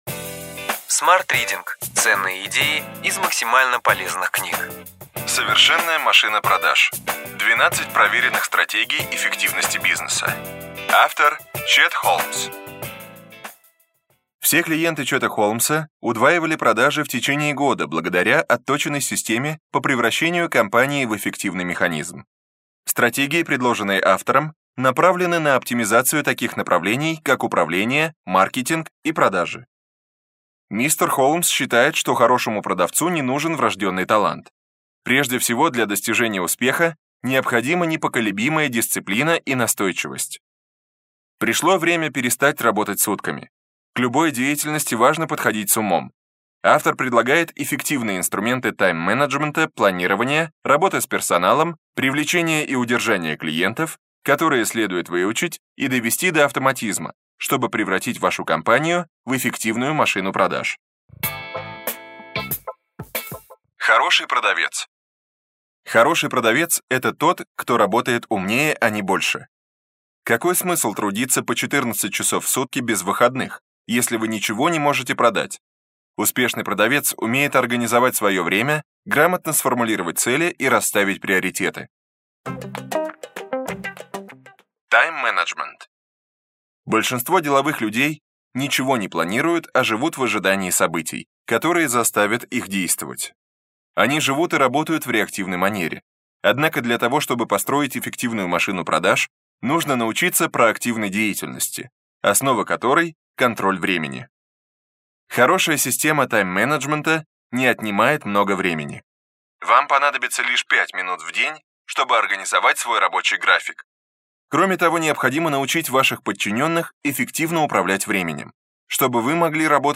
Аудиокнига Ключевые идеи книги: Совершенная машина продаж. 12 проверенных стратегий эффективности бизнеса. Чет Холмс | Библиотека аудиокниг